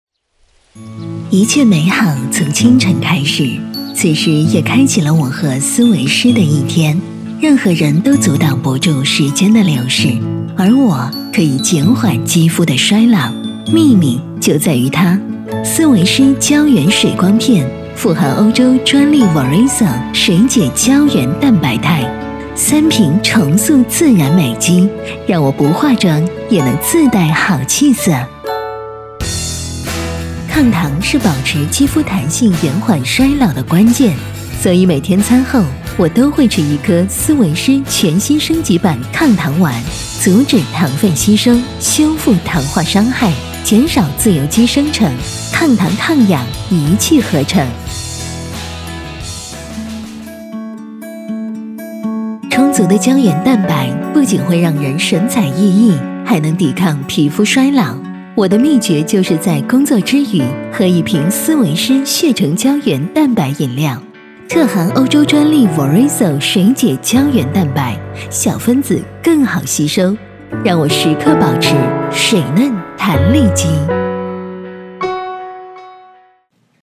女97-广告 - 知性柔和-Swisse斯维诗系列广告
女97全能配音 v97
女97-广告---知性柔和-Swisse斯维诗系列广告.mp3